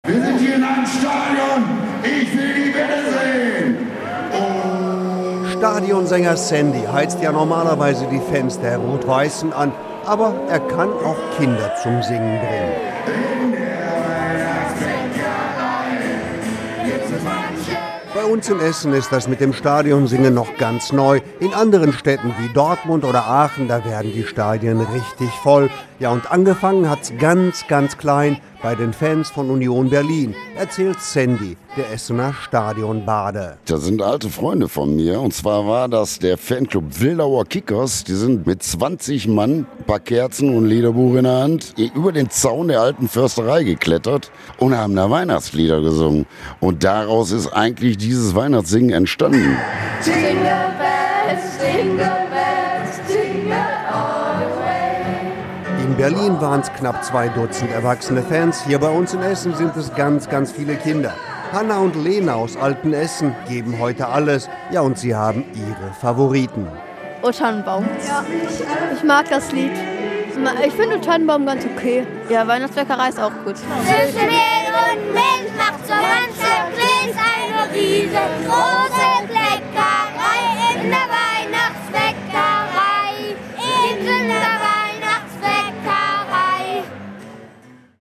Essen singt beim großen Weihnachtssingen im Stadion - Radio Essen
Weihnachtszauber schallte wieder durch den Norden von Essen. Einige Tausend Menschen sangen Weihnachtslieder im Stadion an der Hafenstraße.